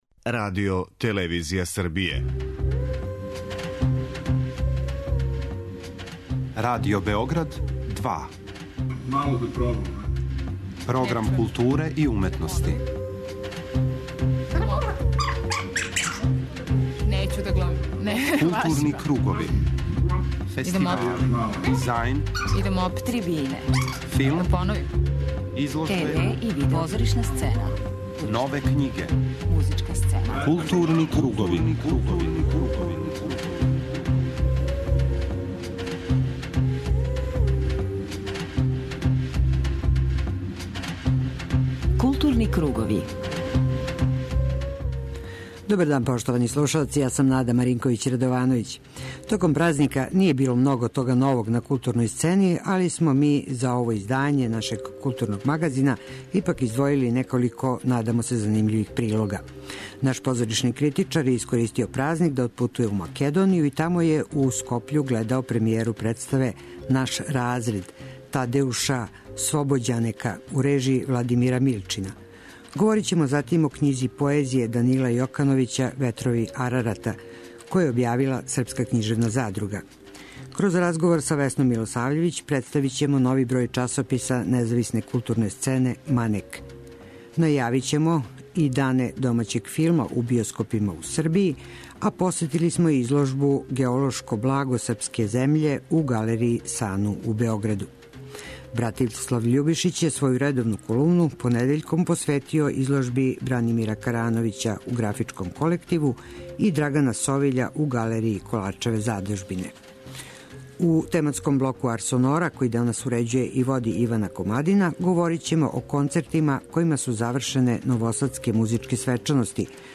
преузми : 54.88 MB Културни кругови Autor: Група аутора Централна културно-уметничка емисија Радио Београда 2.